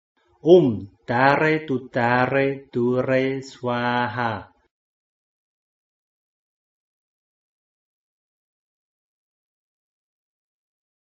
绿度母心咒（念诵）